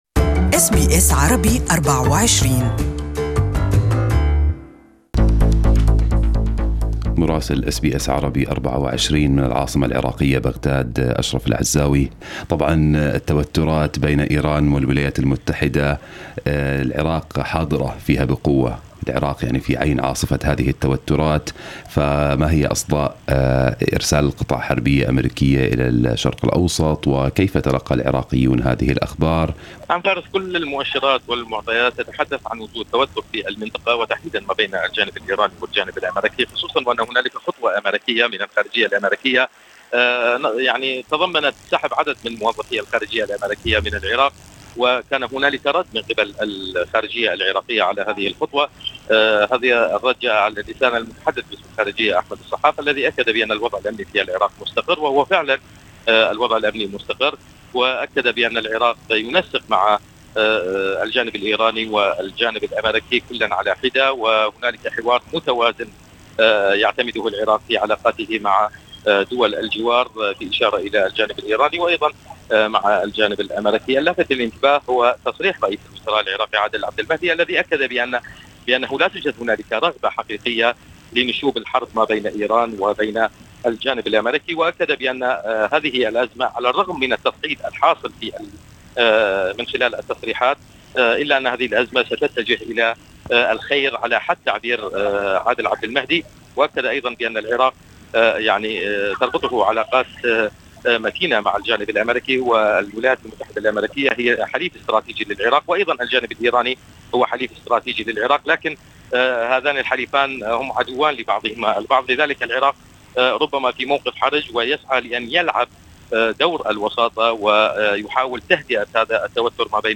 Listen to the full report from our correspondent in Arabic above Share